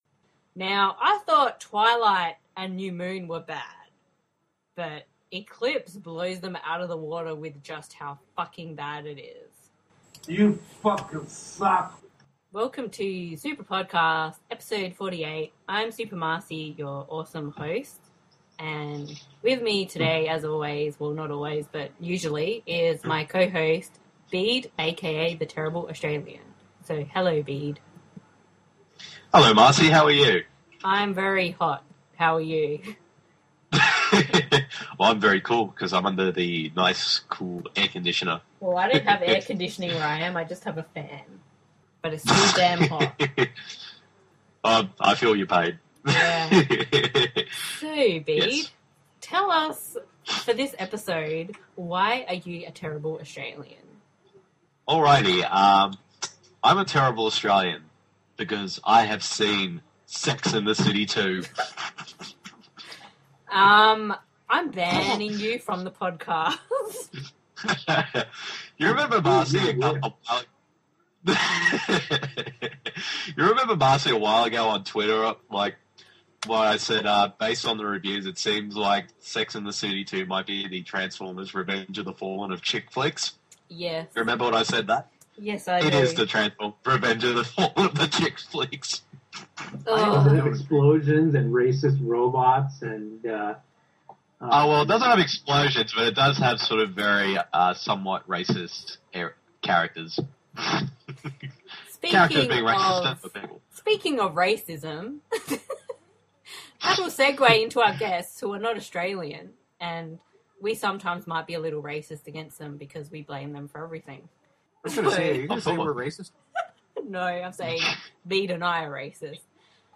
As usually, when the Australian’s and Candian’s are all together, the show is slightly insane.